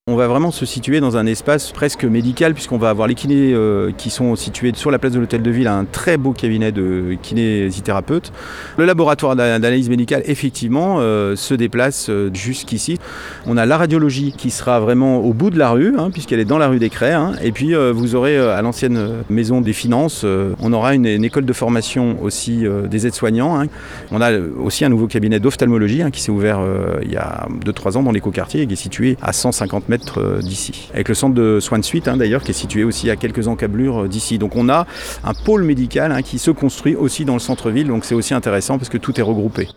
Stéphane Valli est le maire de Bonneville.